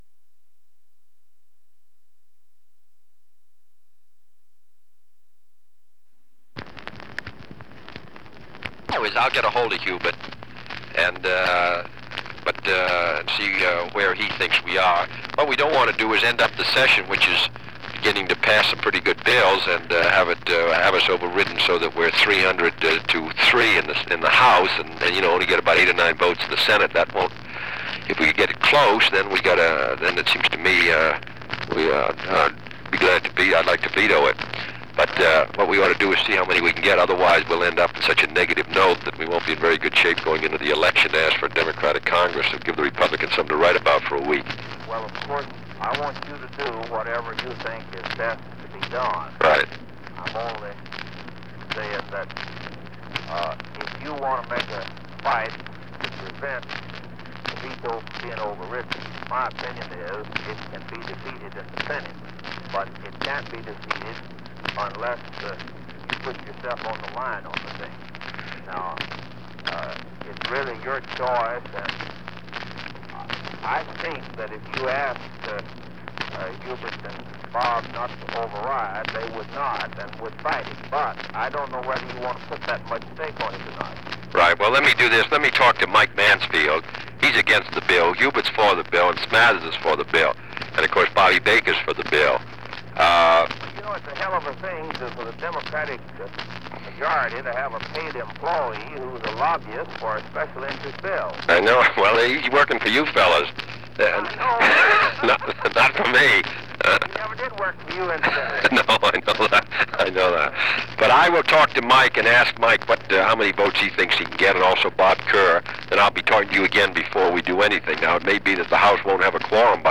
Conversation with Eugene Keogh
Secret White House Tapes | John F. Kennedy Presidency Conversation with Eugene Keogh Rewind 10 seconds Play/Pause Fast-forward 10 seconds 0:00 Download audio Previous Meetings: Tape 121/A57.